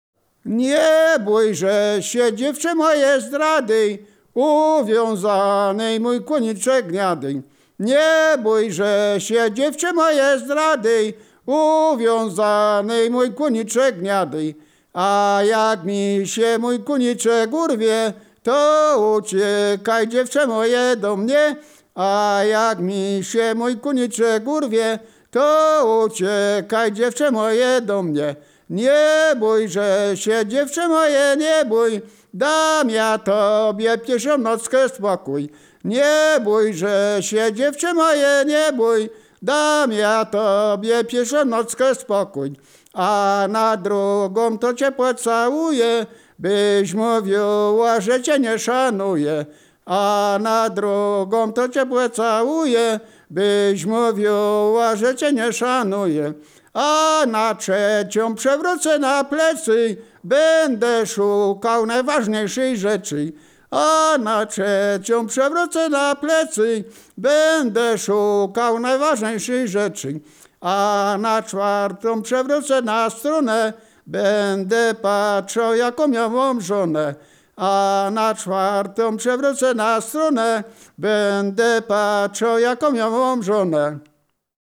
województwo wielkopolskie, powiat gostyński, gmina Krobia, wieś Stara Krobia
liryczne miłosne